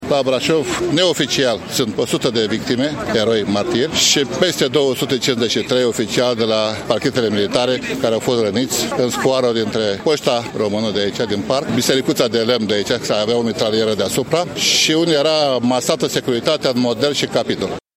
Aproximativ 200 de oameni au participat la momentul solemn care a avut loc la Cimitirul Eroilor din centrul Brașovului.